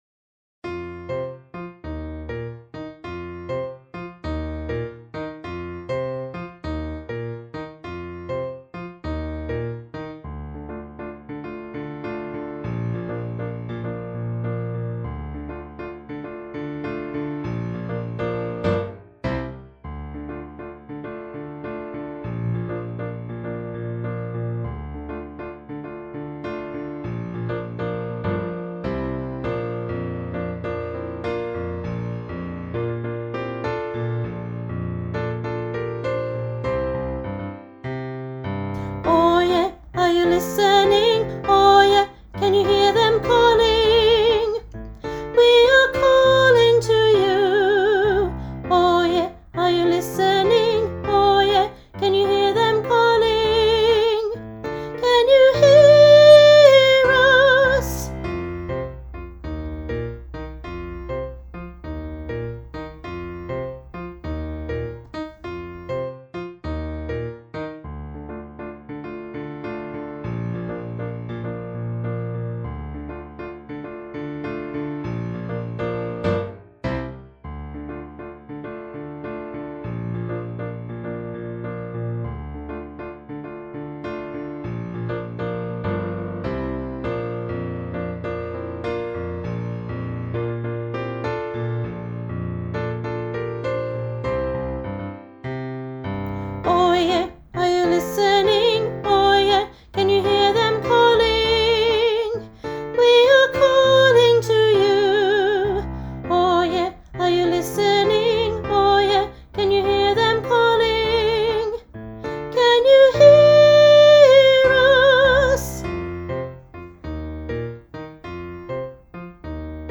Elementary Choir – Oye